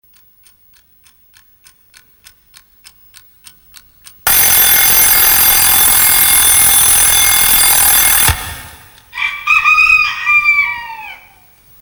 ebreszto.mp3